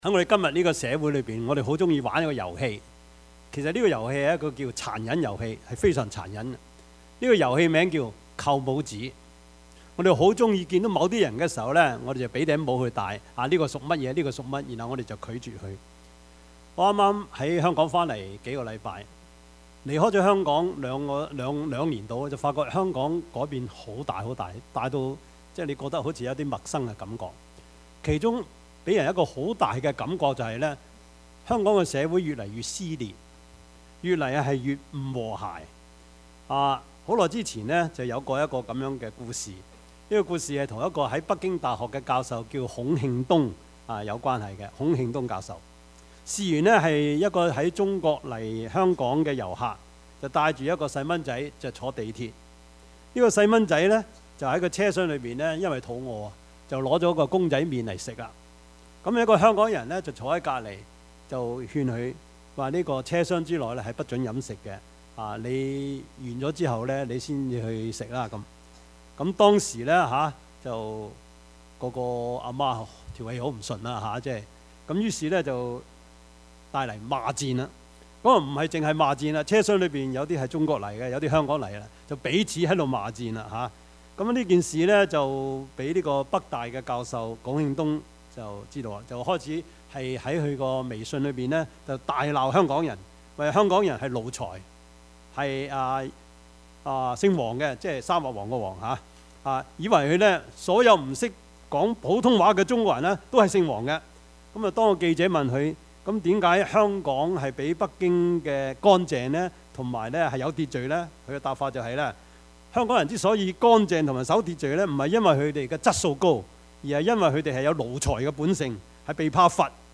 Service Type: 主日崇拜
Topics: 主日證道 « 軟弱變剛強 機不可失 »